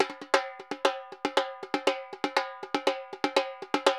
Repique Candombe 120_1.wav